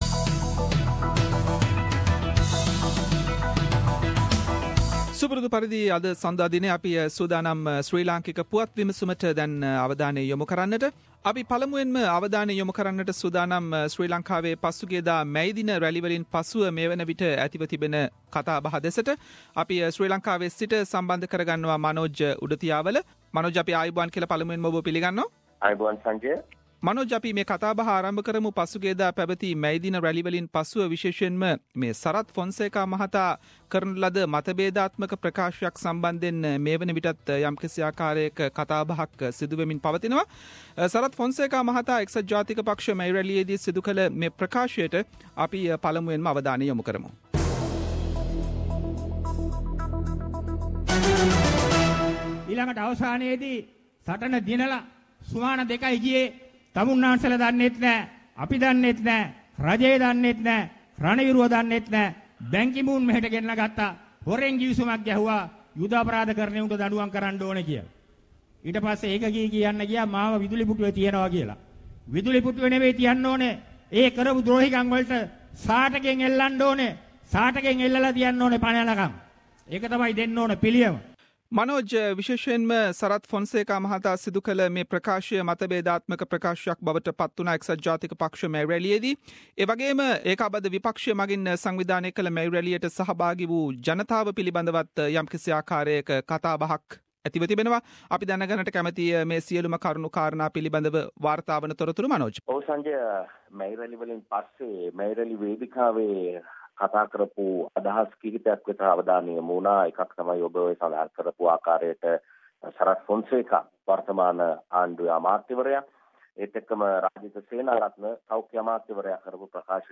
Weekly Sri Lankan political highlights - The comprehensive wrap up of the highlighted political incidents in Sri Lanka…..Senior Journalist - News and current affair